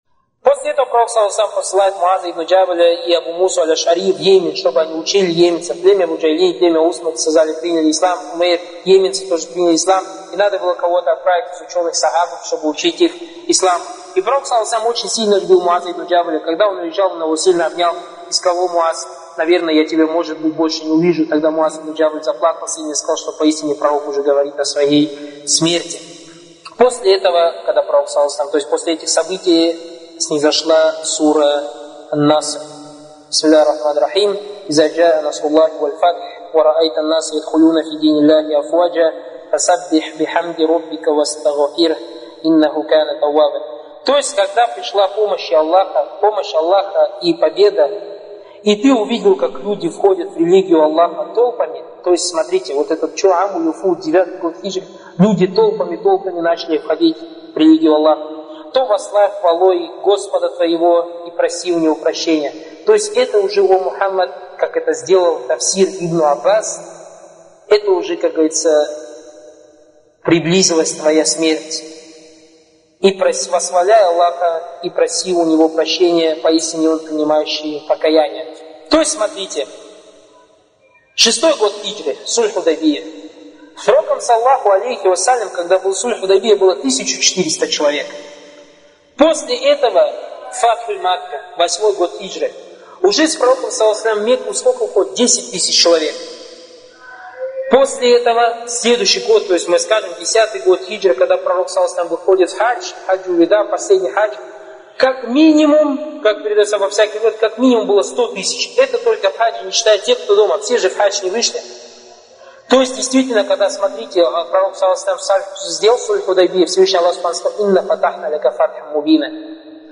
лекции Торик Суейдана (были приняты во внимание его ошибки, на которые указали учёные).